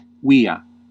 Ääntäminen
US : IPA : [ˈmæ.nɜː]